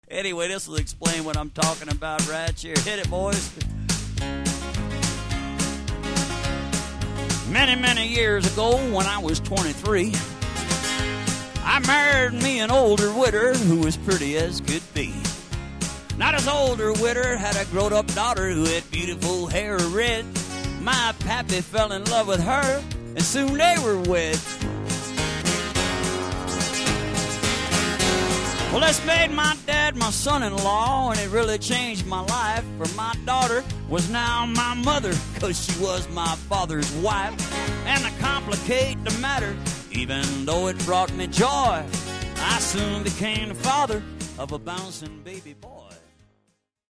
Recorded LIVE in Deadwood, South Dakota